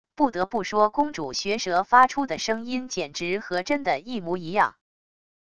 不得不说公主学蛇发出的声音简直和真的一模一样wav音频